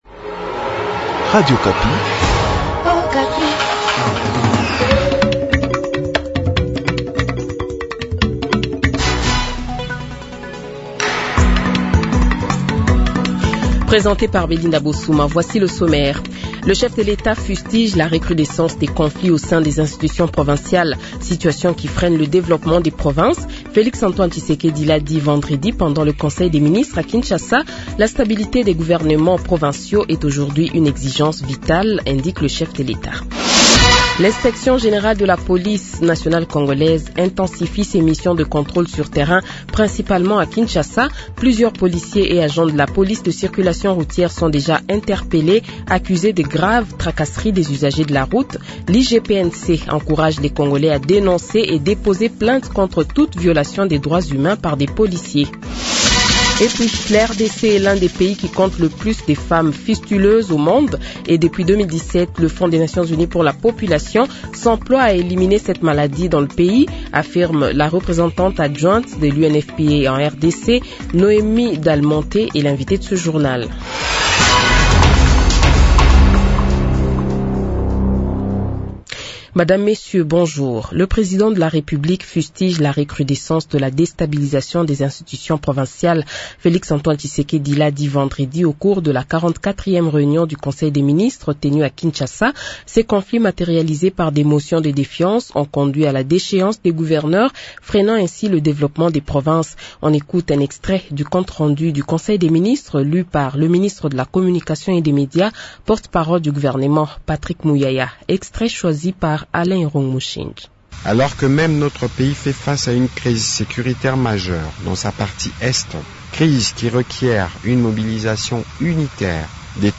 Le Journal de 7h, 25 Mai 2025 :